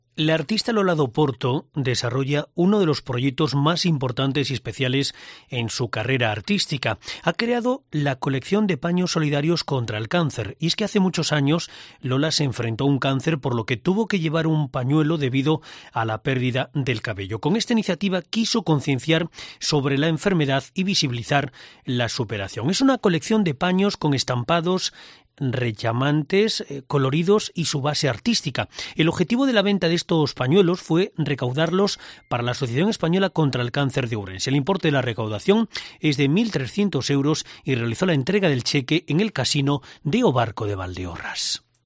Ahora crea una colección de pañuelos solidarios contra el cáncer. En una entrevista en Cope Ourense